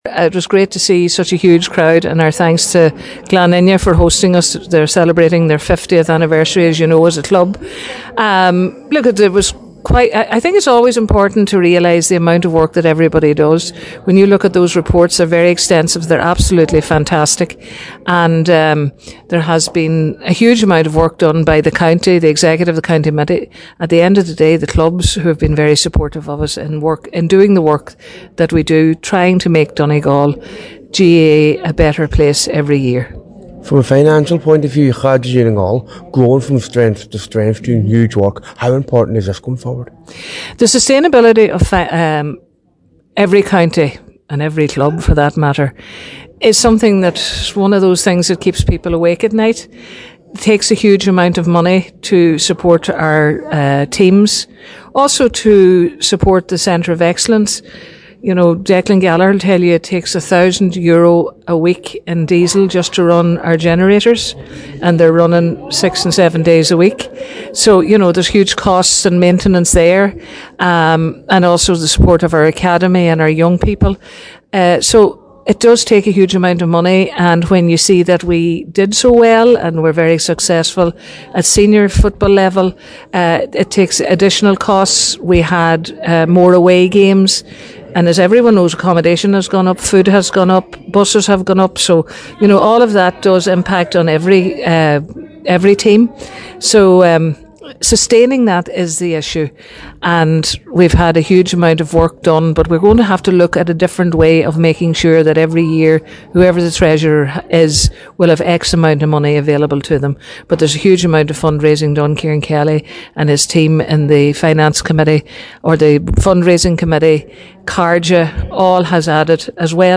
Donegal GAA held their annual County Convention on Wednesday evening in Ballybofey.
mary-c-convention.mp3